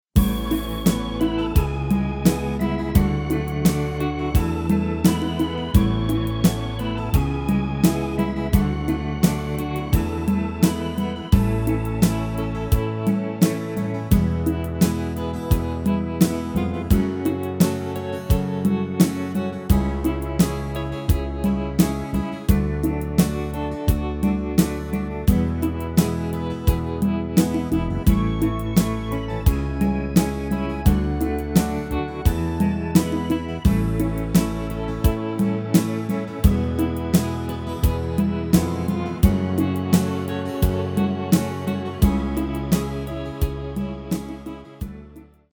Demo/Koop midifile
Genre: Evergreens & oldies
Toonsoort: A
- Géén vocal harmony tracks